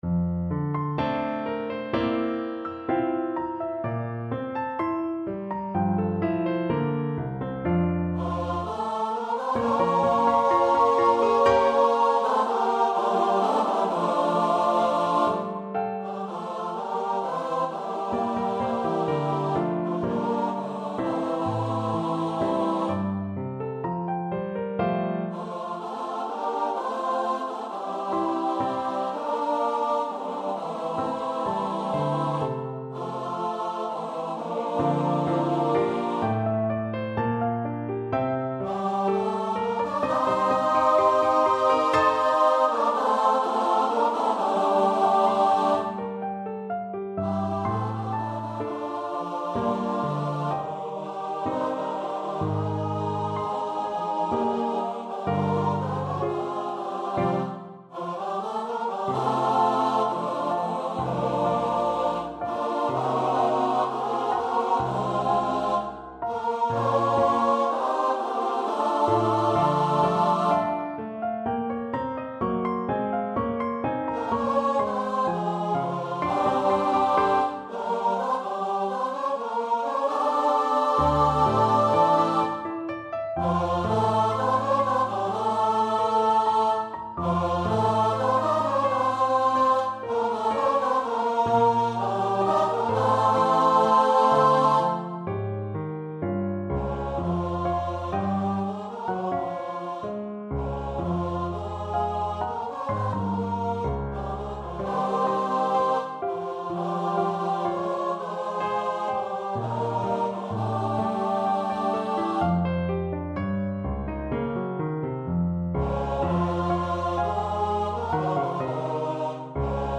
I Think I Could Turn and Live With Animals for SATB Voice and Piano
play sound (not a recording, but an electronic version - just to get a sense of the sound)